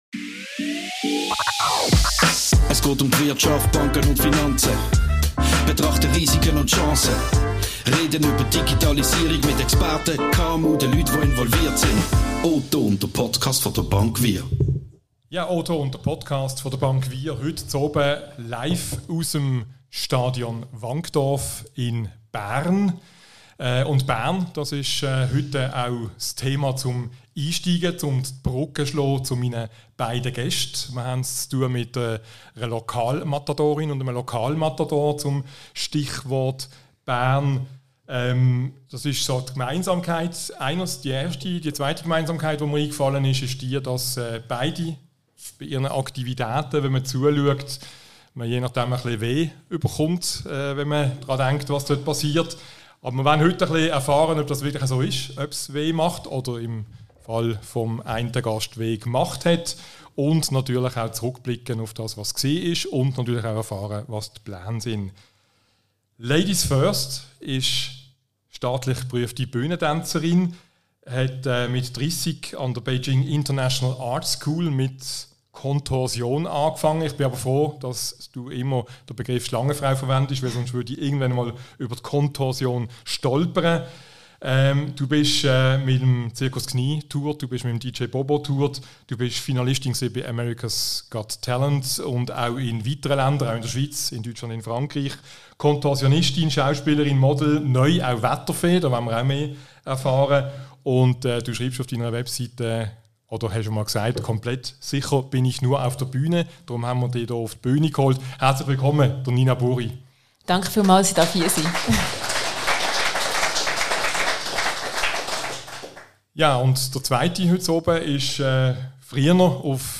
Schlangenfrau trifft Eishockey-Legende – das Ergebnis ist ein sehr unterhaltsamer Live-Podcast im Stadion Wankdorf in Bern.